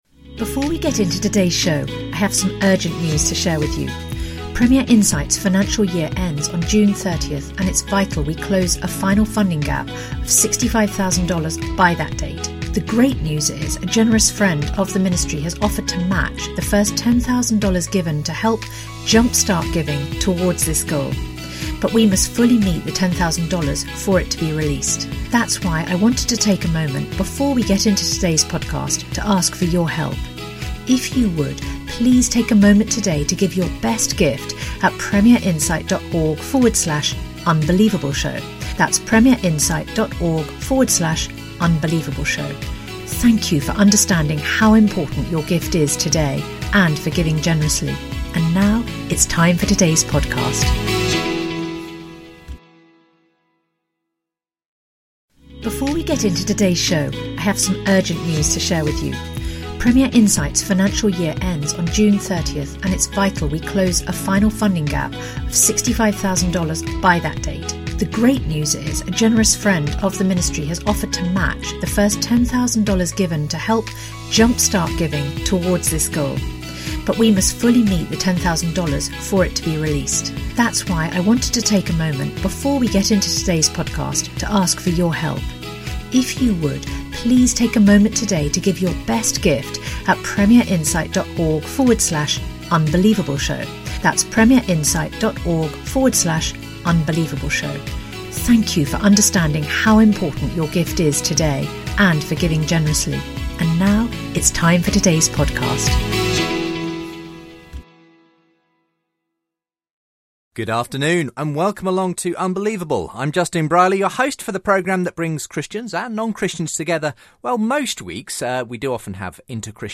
The show concludes with highlights from the Panel Q&A at the end of the day.